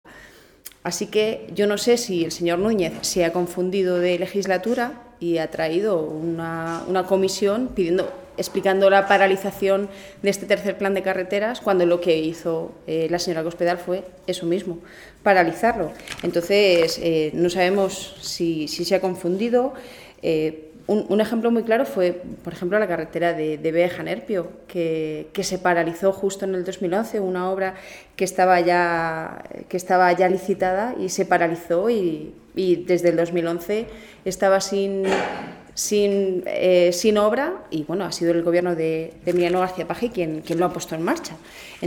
La diputada del Grupo Parlamentario Socialista en las Cortes de Castilla-La Mancha, Rosario García, ha destacado la buena marcha de las obras en carreteras que se está llevando a cabo por parte del gobierno del presidente García-Page.
Cortes de audio de la rueda de prensa